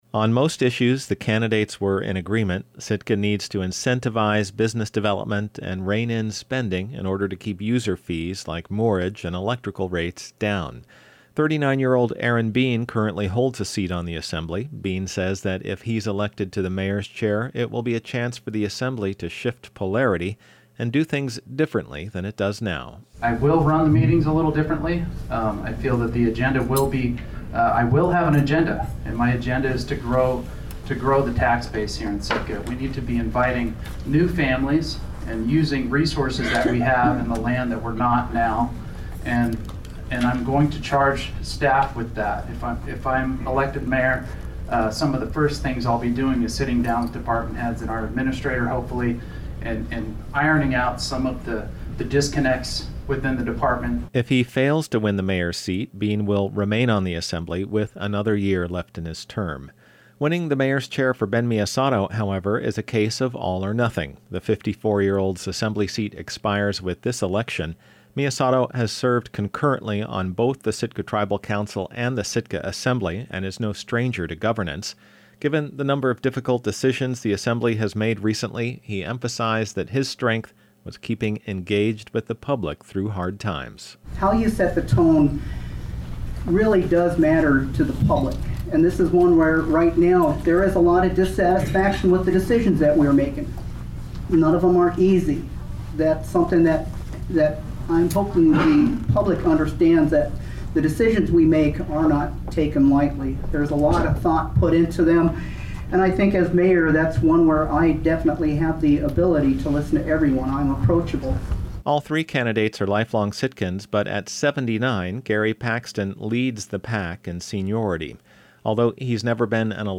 Sitka’s three candidates for mayor met in a final campaign event before next Tuesday’s municipal election.